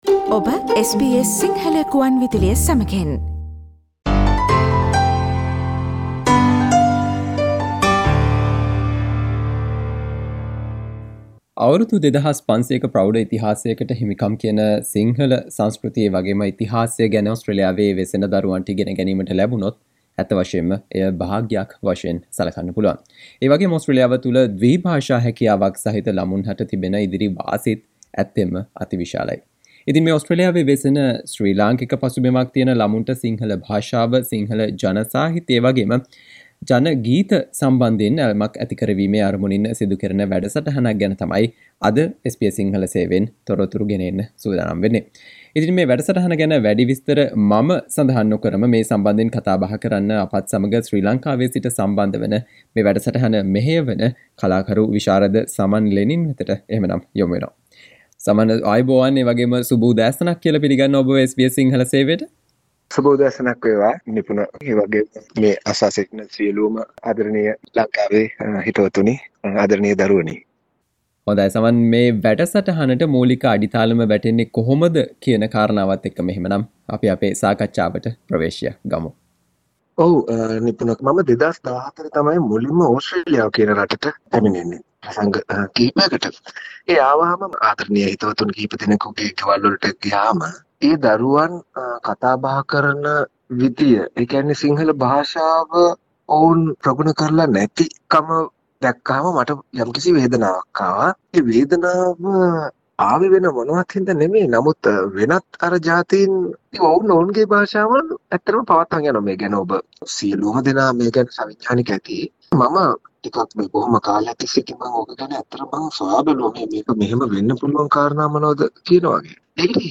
SBS සිංහල සේවය සිදු කල සාකච්චාව